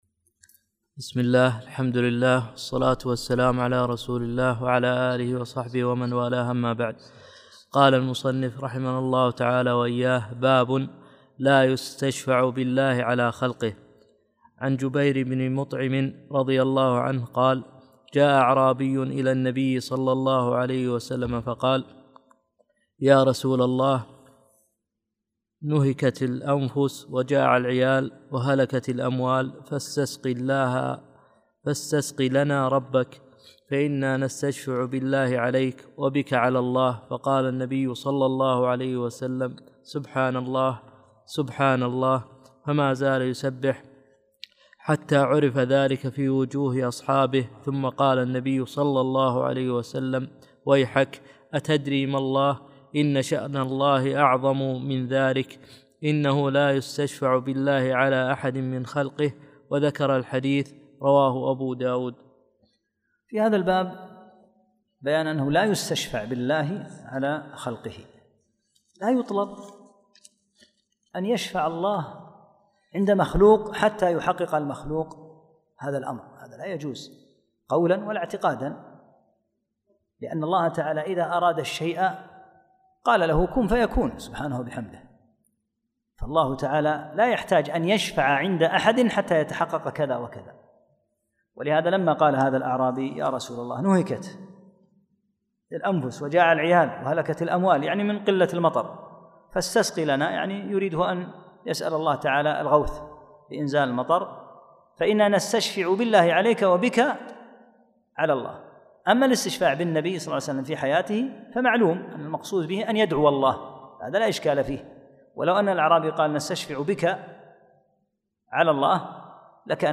54 - الدرس الرابع والخمسون